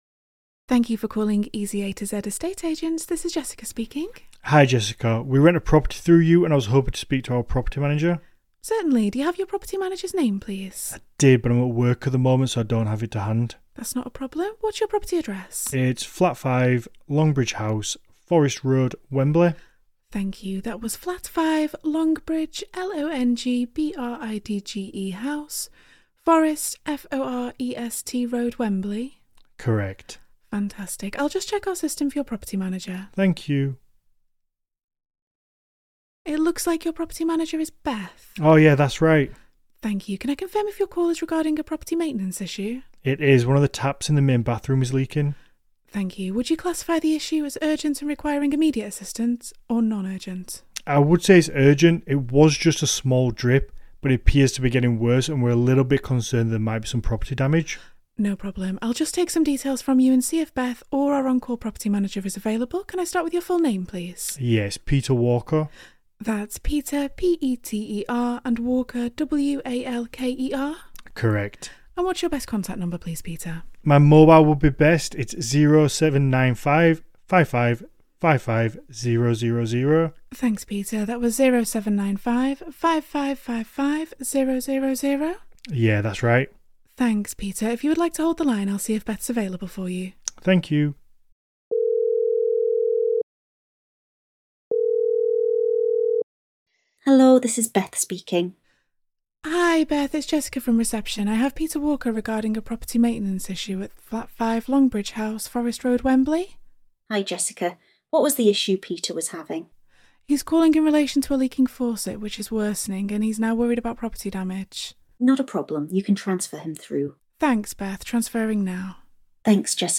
• UK receptionists
phone-answering-virtual-assistant-sample-call-MyAssistant.mp3